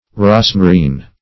Rosmarine \Ros"ma*rine\, n. [Norw. rosmar a walrus; ros a horse